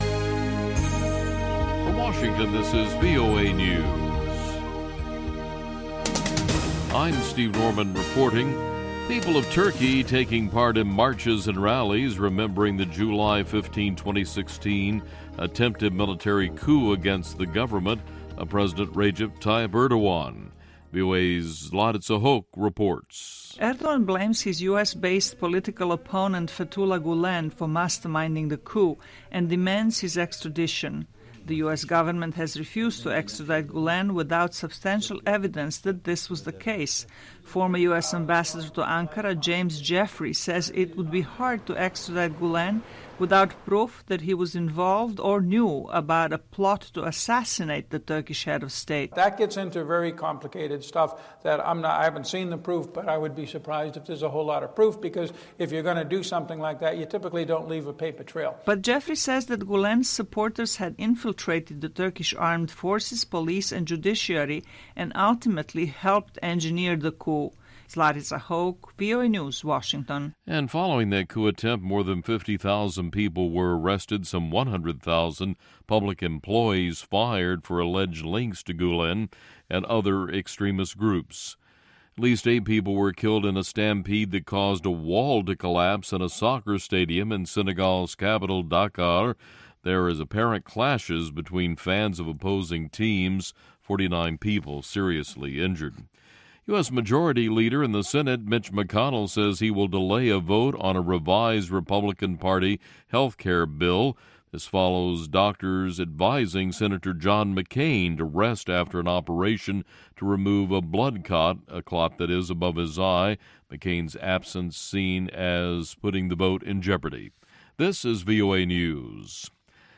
He is a drummer and composer from Nigeria.